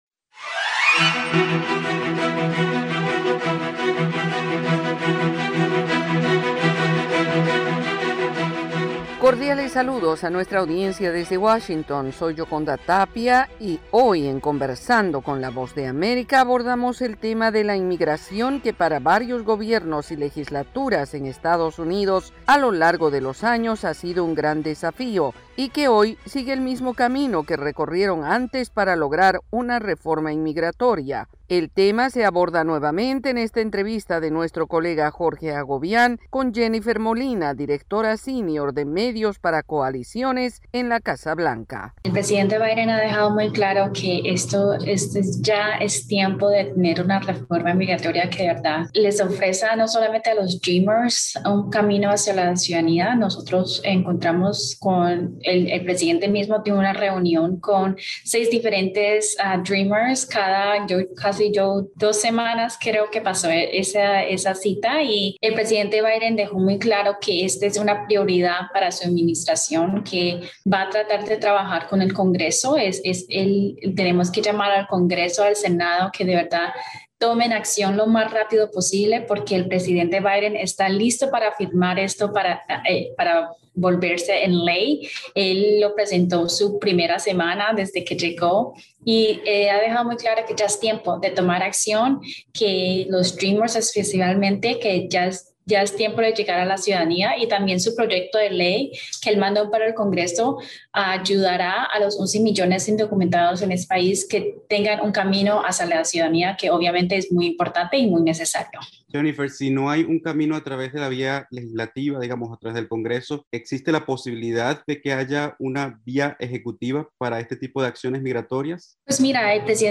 Conversamos con James Story, embajador de EE.UU. para Venezuela y que cumple sus funciones desde Colombia, hablando sobre la situación en Venezuela.